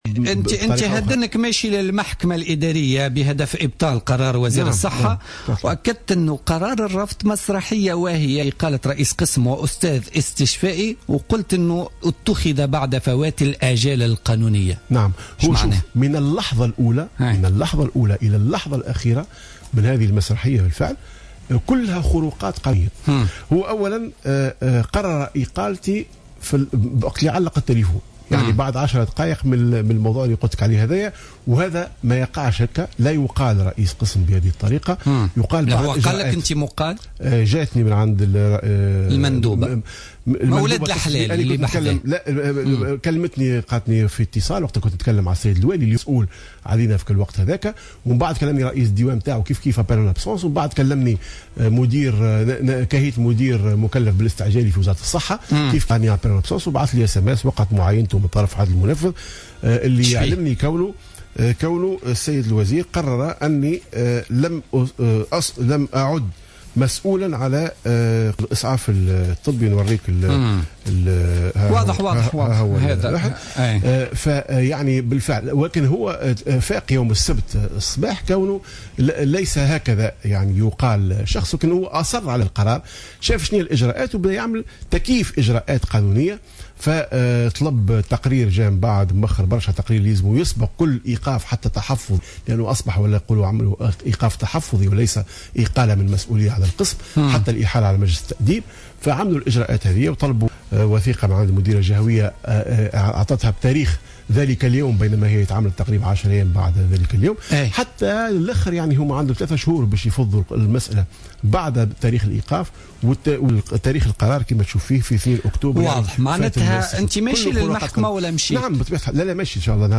في تصريح للجوهرة أف أم في برنامج بوليتكا